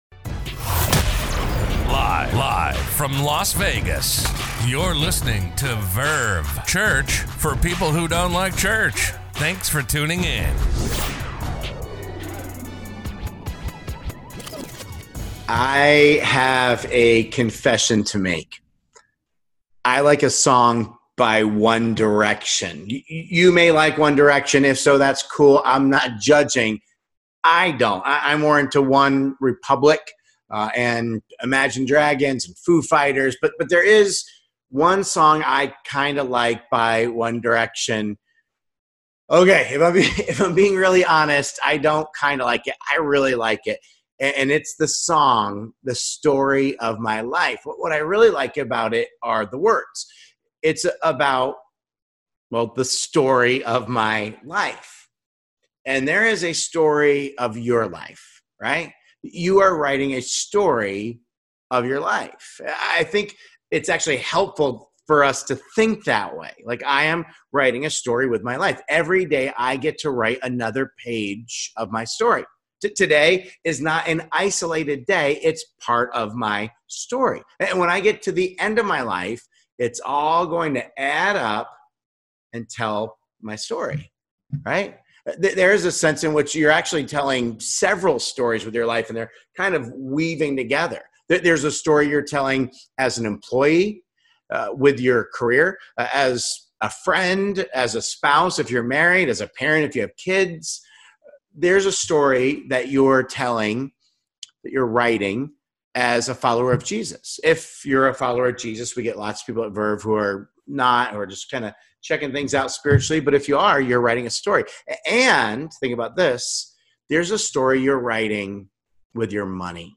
A message from the series "What If?." Have you ever thought about the fact that your life is telling a story?